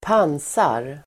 Uttal: [²p'an:sar]